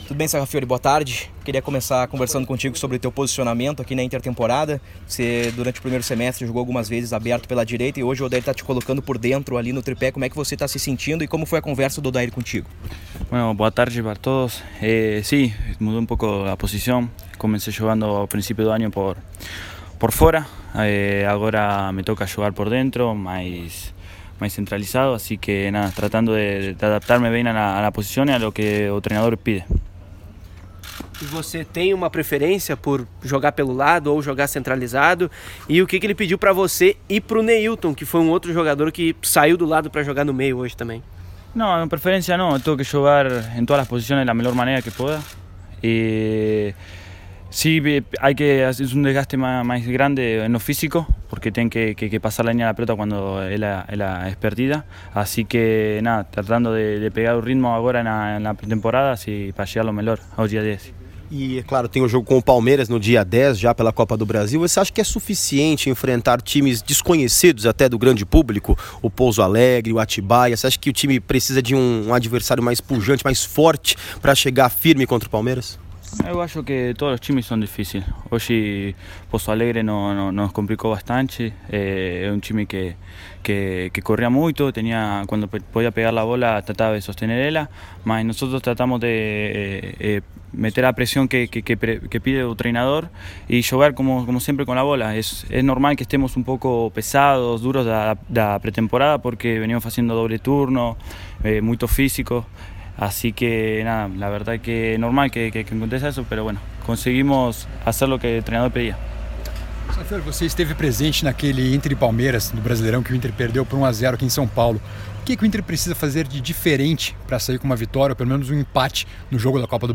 Coletiva-Sarrafiore-.mp3